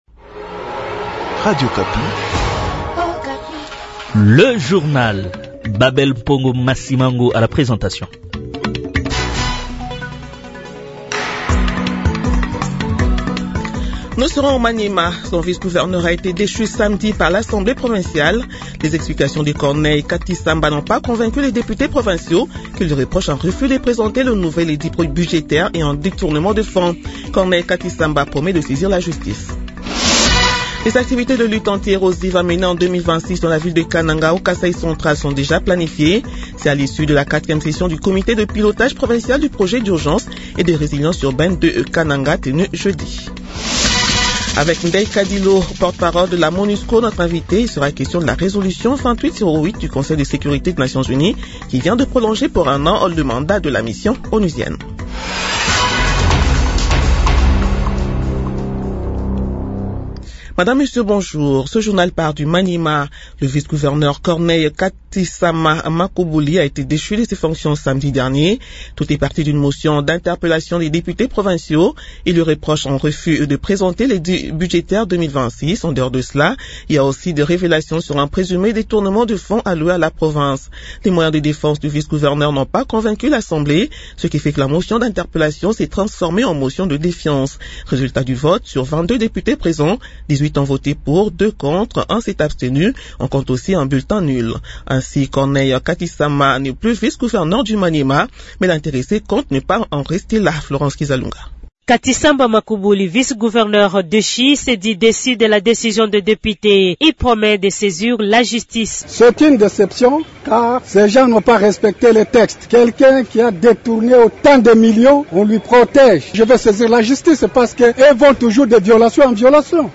JOURNAL 8 H DU LUNDI 22 DECEMBRE 2025